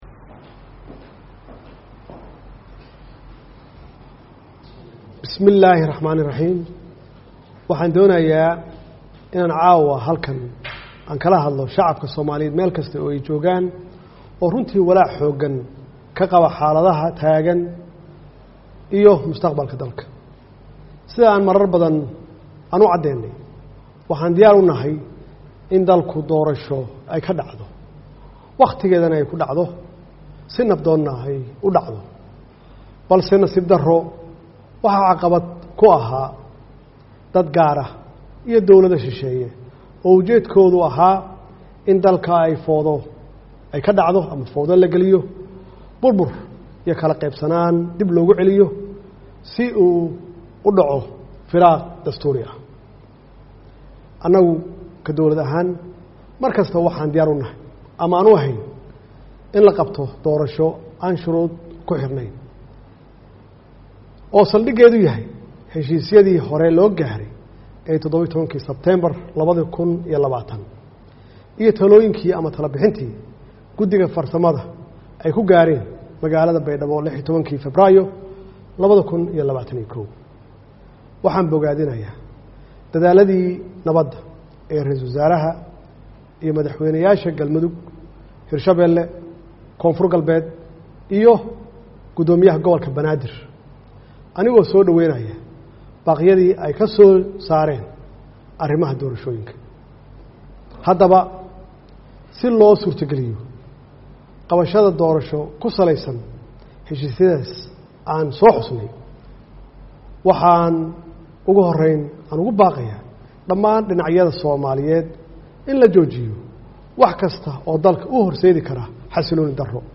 Khudbadda Farmaajo